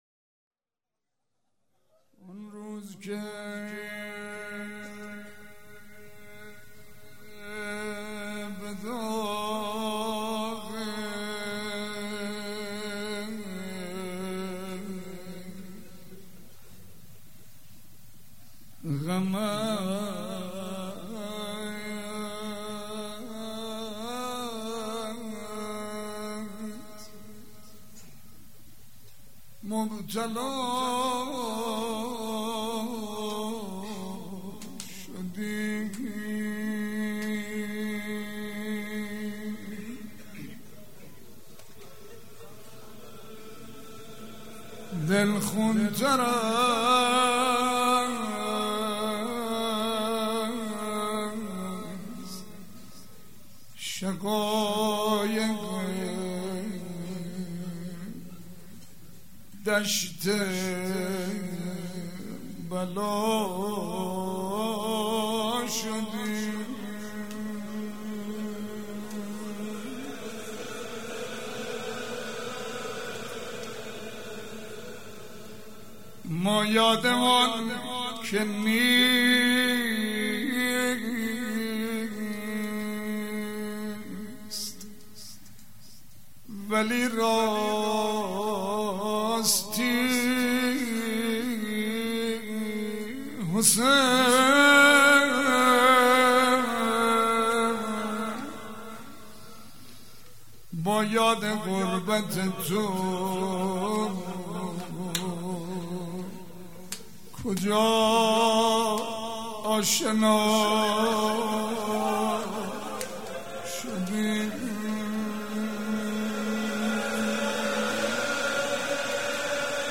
هیئت ریحانه الحسین (س)/ سال 1397